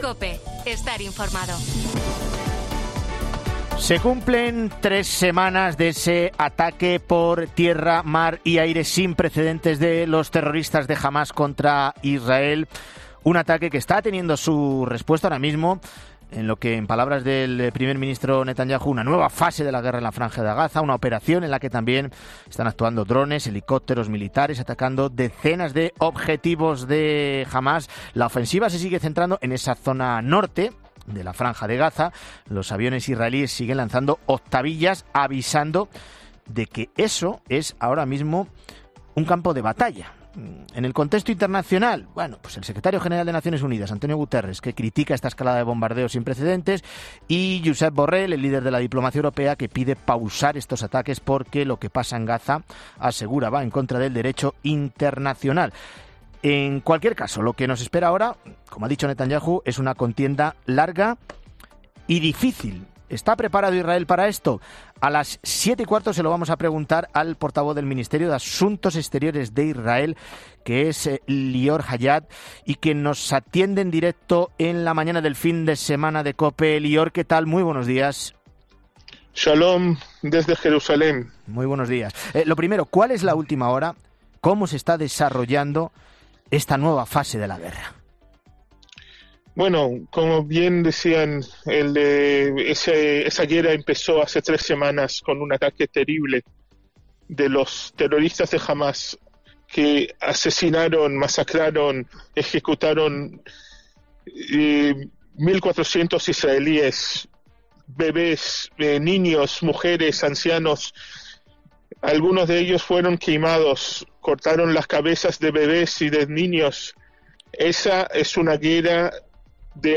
El portavoz del Ministerio de Asuntos Exteriores de Israel, Lior Haiat, explicaba en 'La Mañana Fin de Semana' sus objetivos en esta "nueva fase de...